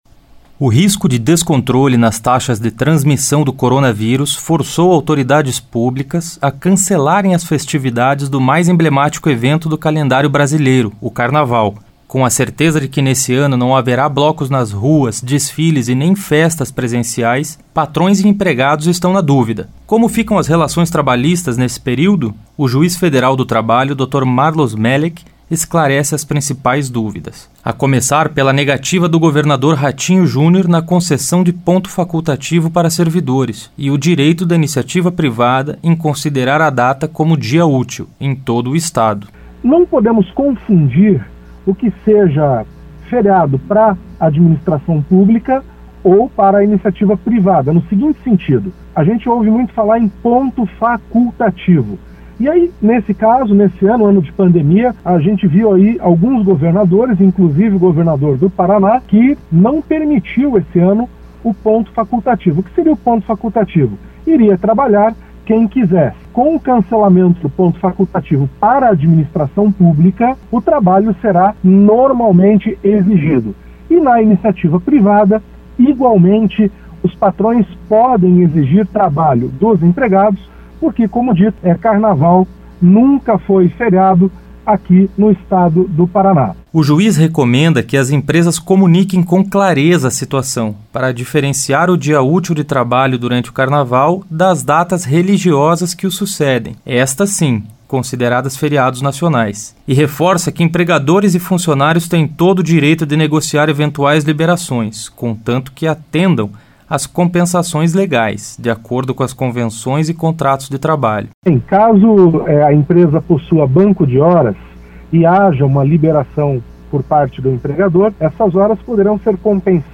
O juiz federal do trabalho, Dr. Marlos Melek esclarece as principais dúvidas.